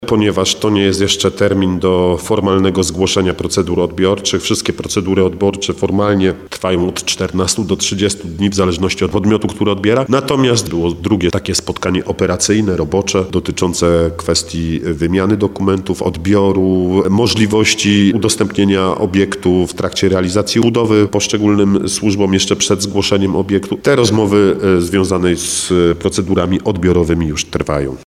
– Te procedury jeszcze się nie rozpoczęły – mówi Artur Bochenek, zastępca prezydenta Nowego Sącza.